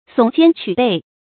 耸肩曲背 sǒng jiān qū bèi
耸肩曲背发音
成语注音ㄙㄨㄙˇ ㄐㄧㄢ ㄑㄩˇ ㄅㄟˋ